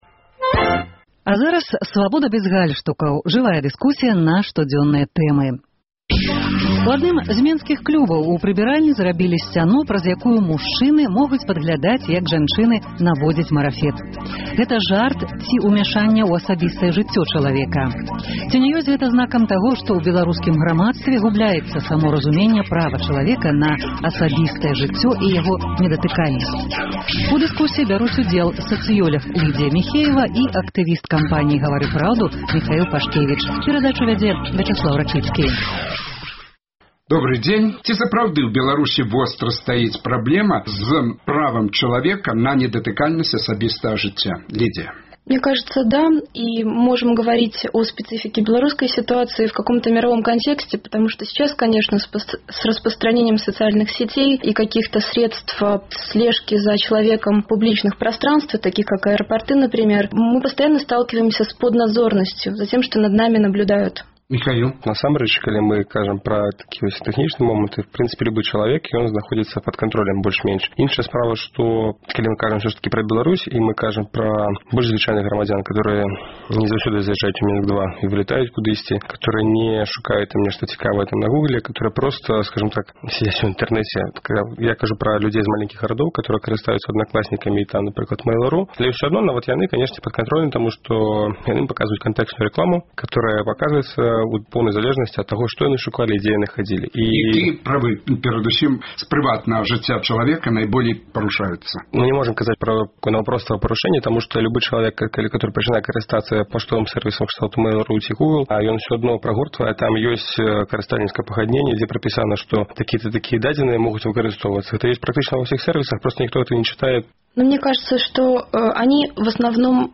У дыскусіі